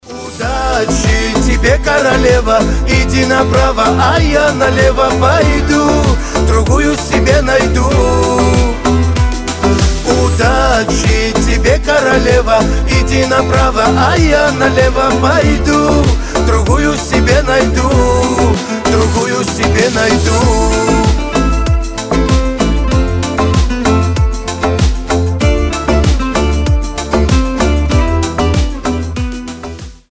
• Качество: 320, Stereo
кавказские